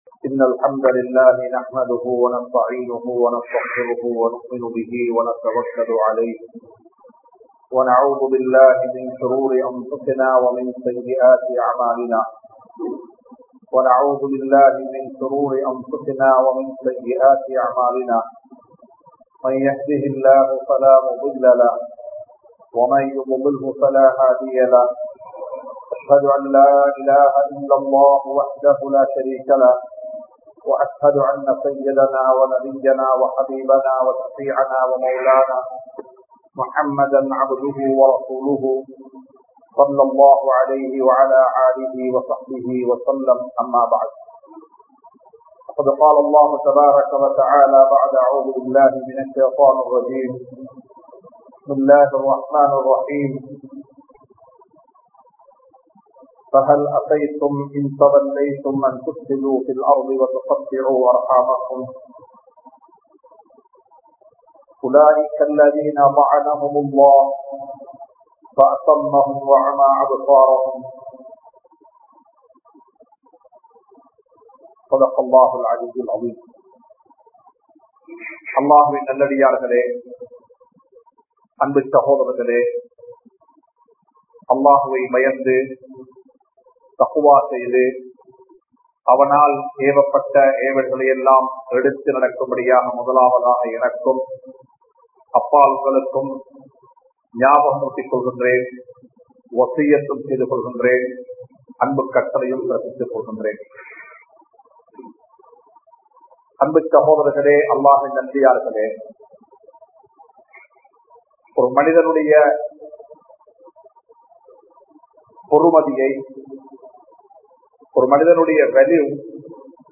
Iraththa Uravuhalai Searnthu Vaalungal (இரத்த உறவுகளை சேர்ந்து வாழுங்கள்) | Audio Bayans | All Ceylon Muslim Youth Community | Addalaichenai
Colombo 04, Majma Ul Khairah Jumua Masjith (Nimal Road)